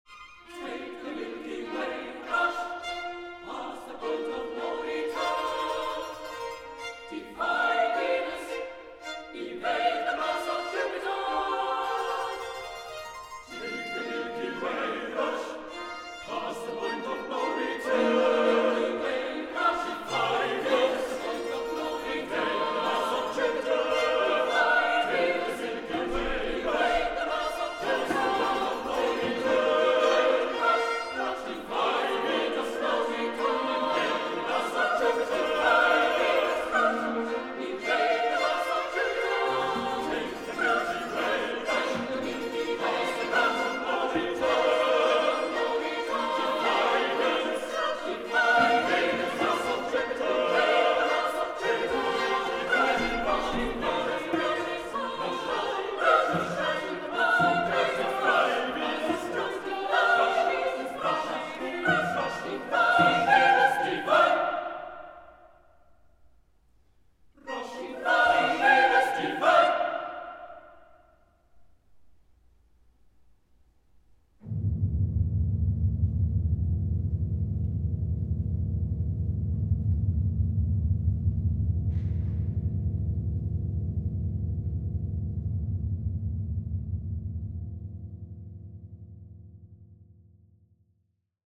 för blandad kör, barockorkester och publik ad lib.